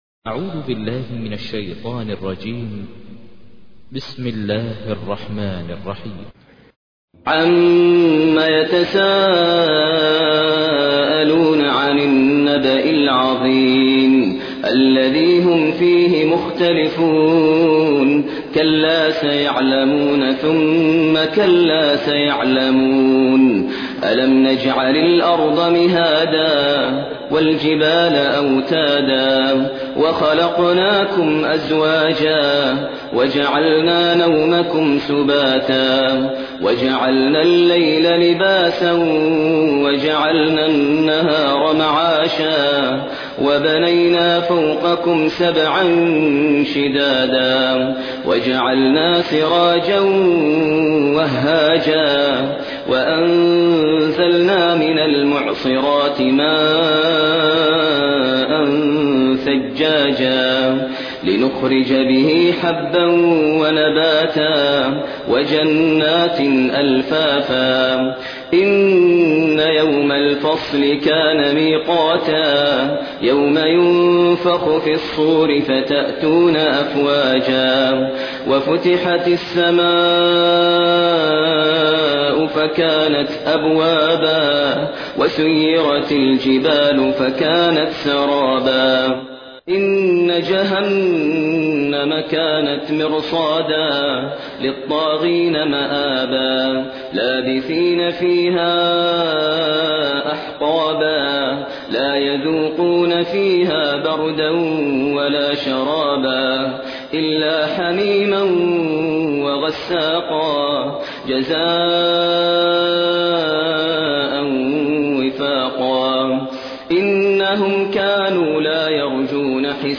تحميل : 78. سورة النبأ / القارئ ماهر المعيقلي / القرآن الكريم / موقع يا حسين